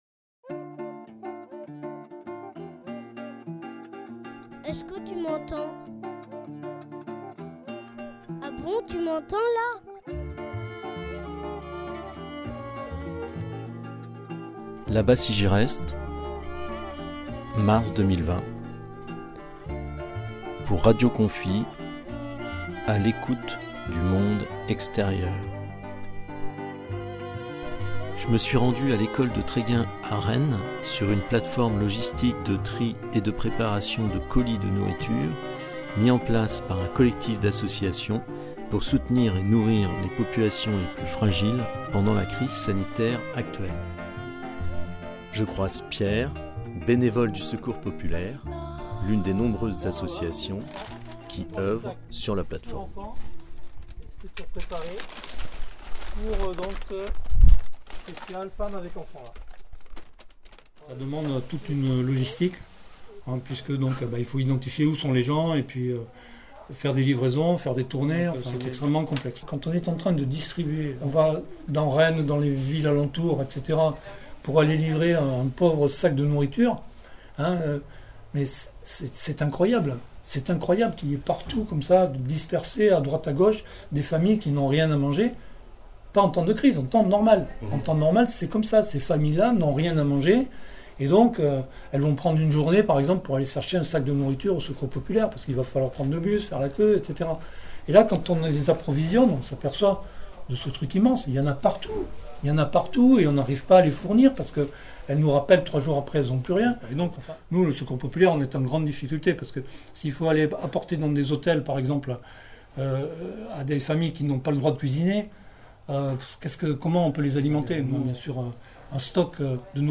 Une capsule sonore réalisée sur la plateforme à écouter ici https